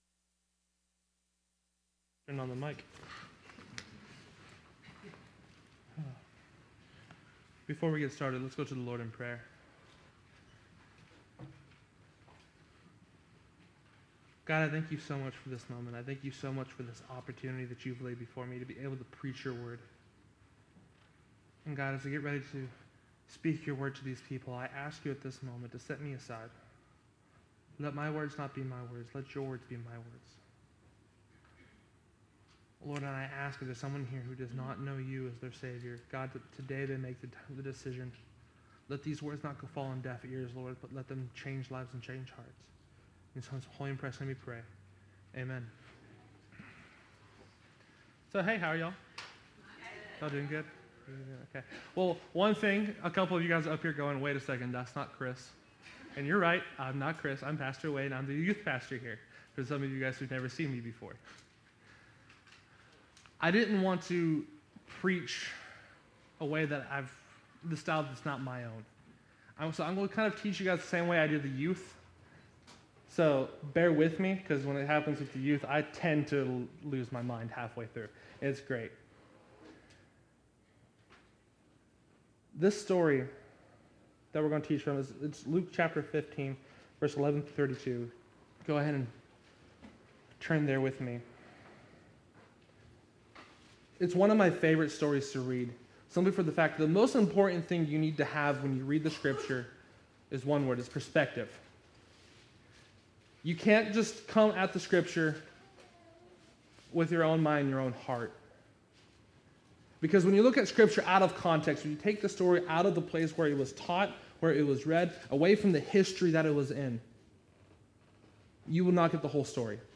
Had the opportunity to preach at First Baptist Church in Decherd, morning service.